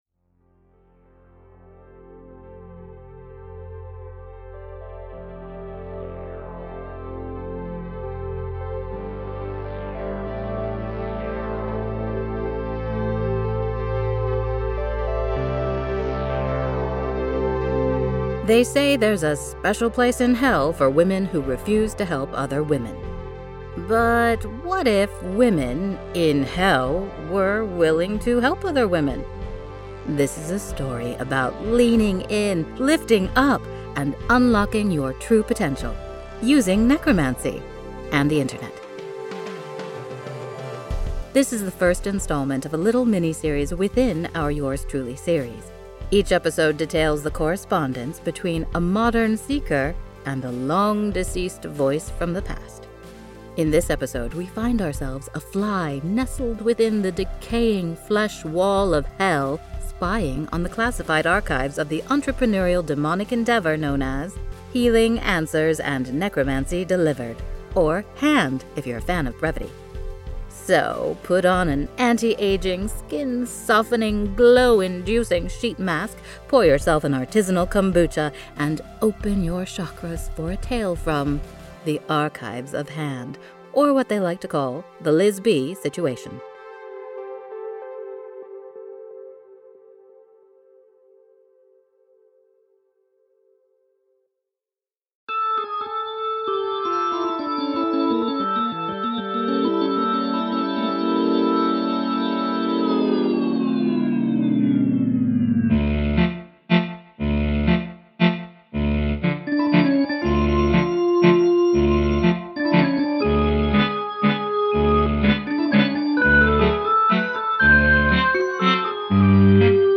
Intro music